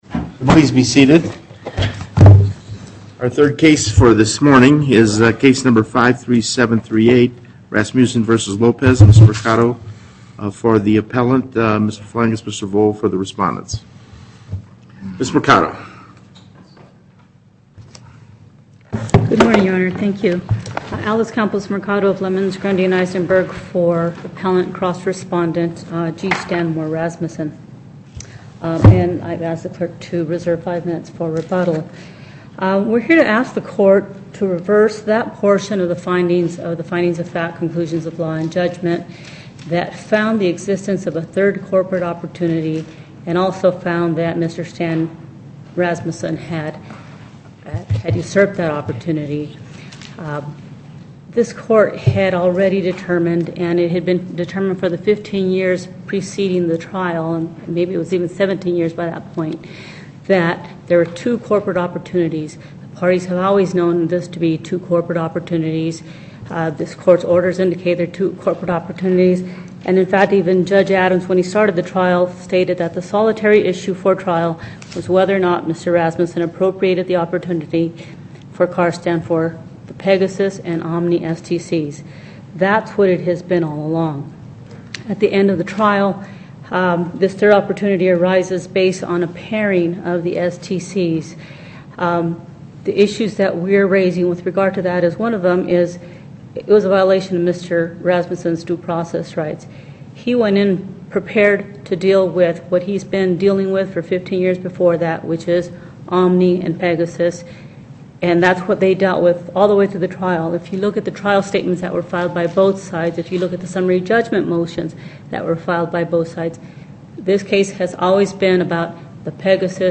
Location: Carson City Northern Panel, Justice Cherry Presiding